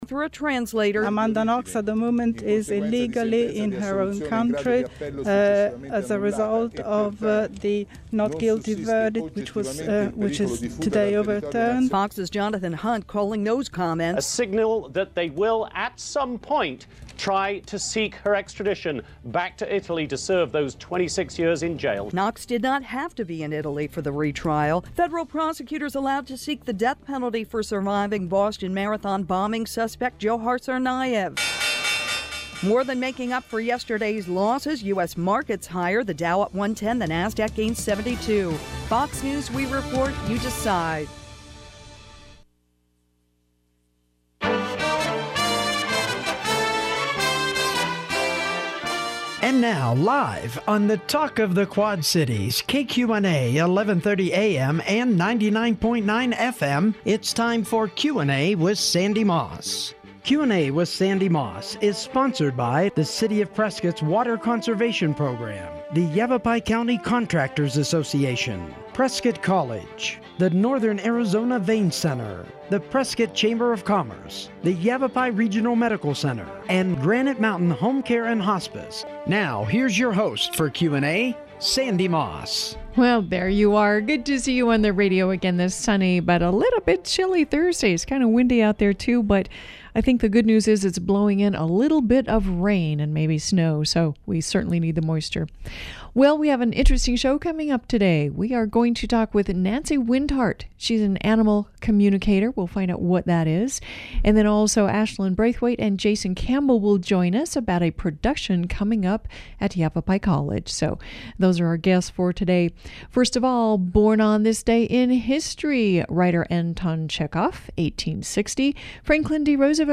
Radio Interview on Animal Communication
(Interview starts at 6 minutes; you can scroll past the ads…it’s commercial radio!)